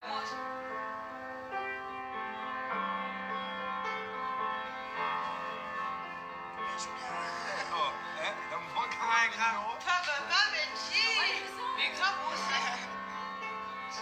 Il me semble qu’elle fait parti d’une scène dans un film assez récent mais impossible de retrouver. Shazam n’arrive pas à trouver, qualité d’enregistrement pas top.